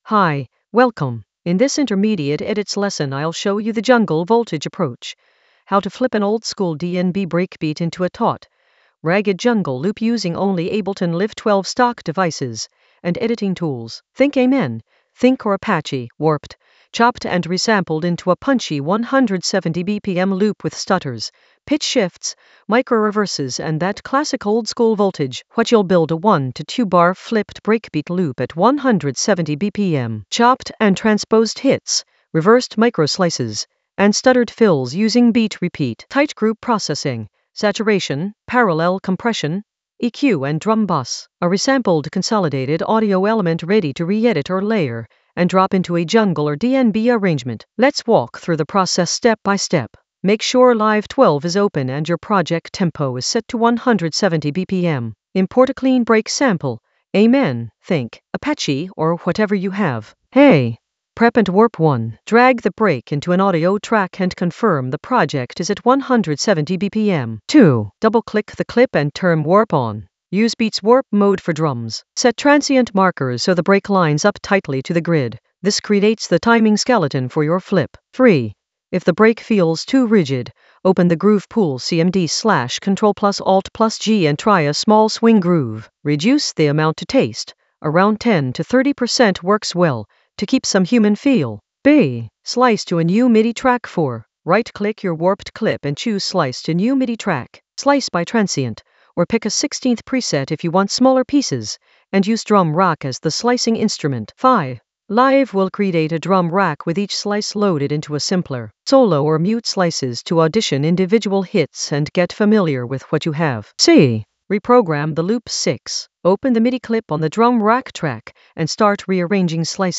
An AI-generated intermediate Ableton lesson focused on Jungle Voltage approach: an oldskool DnB breakbeat flip in Ableton Live 12 for jungle oldskool DnB vibes in the Edits area of drum and bass production.
Narrated lesson audio
The voice track includes the tutorial plus extra teacher commentary.